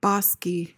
PRONUNCIATION: (BAH-skee) MEANING: adjective: 1.